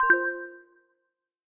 Notification.ogg